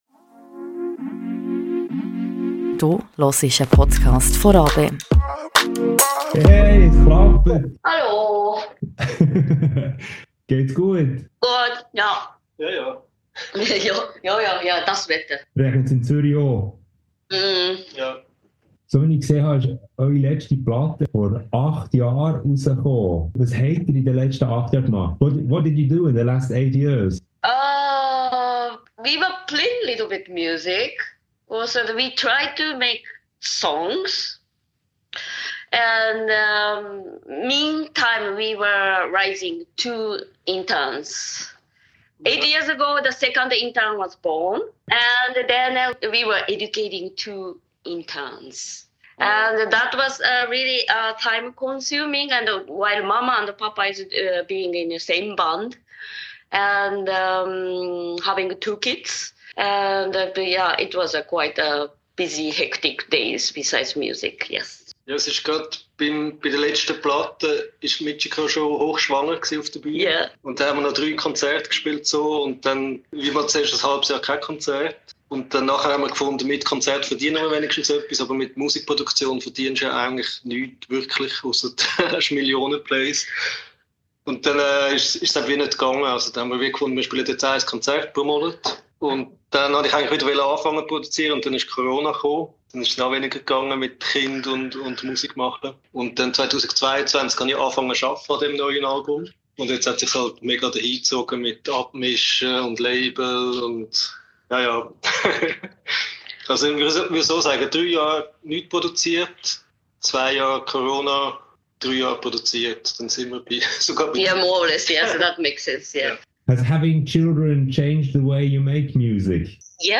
Am Tag vor dem Release den Albums More Or Less Tim & Puma Mimi haben wir über Zoom miteinander geredet, was in den letzten acht Jahren seit dem letzten Album passiert ist, ob die Familie ihre Musik verändert hat, welche Gemüse welche Töne erzeugen und vieles mehr.